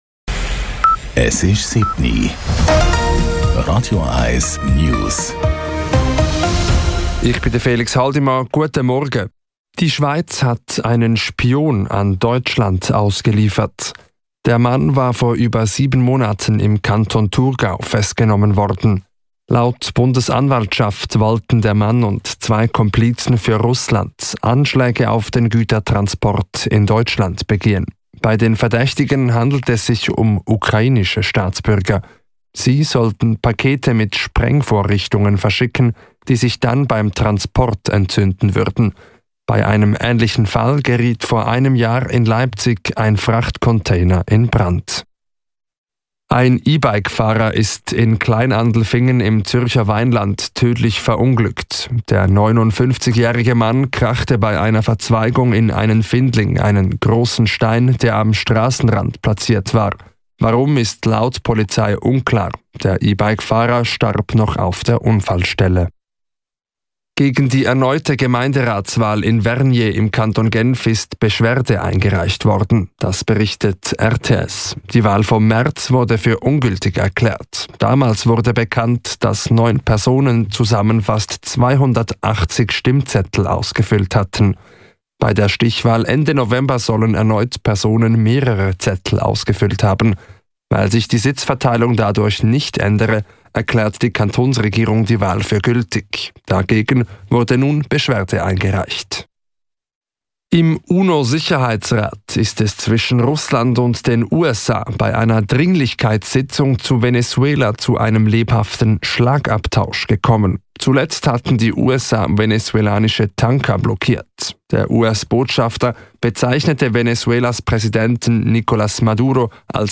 Die letzten News von Radio 1